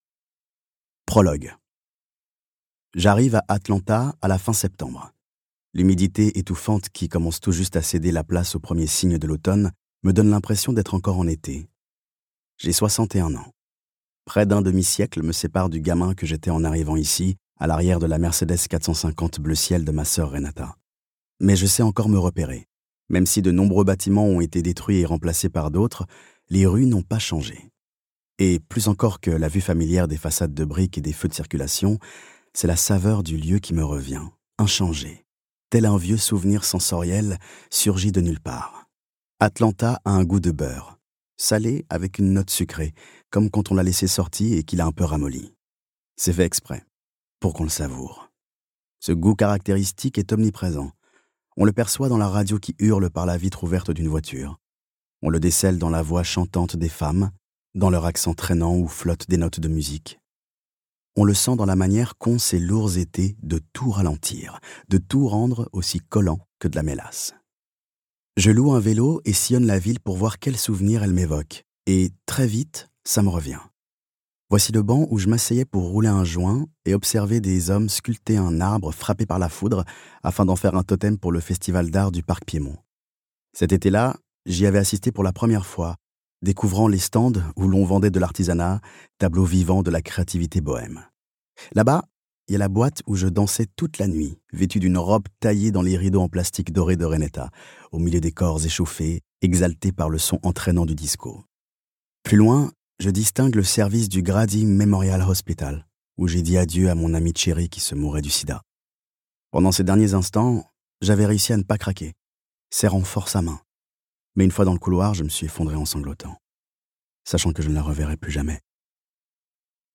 Dans cette autobiographie, la plus connue des drag queens se défait de tous ses artifices et relate l'histoire de sa vie avec une clarté et une tendresse à couper le souffle, laissant émaner sa sagesse à toute épreuve. Ces mémoires honnêtes, poignants et intimes retracent son parcours, de son enfance en tant que jeune garçon noir, pauvre et queer issu d'un foyer brisé, à sa découverte du pouvoir scénique, de la famille choisie, et de l'acceptation de soi.